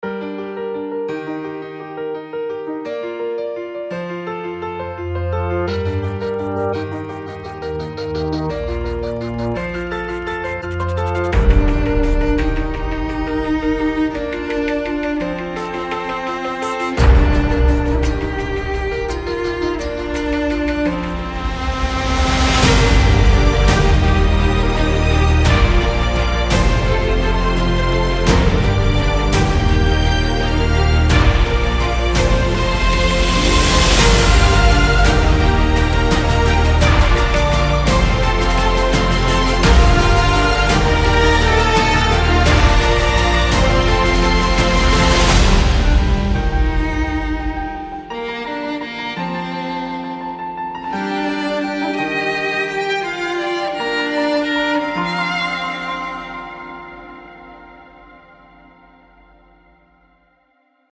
Uvodni-hudba-na-soutezi.mp3